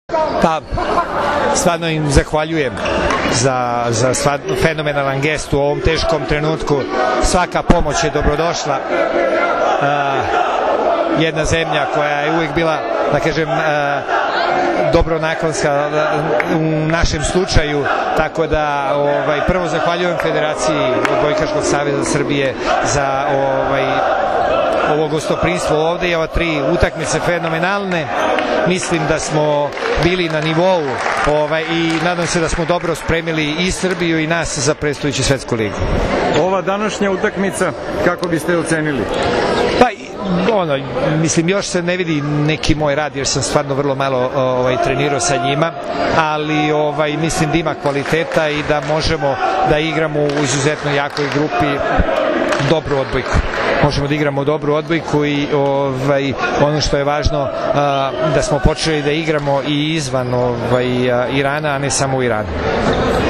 IZJAVA SLOBODANA KOVAČA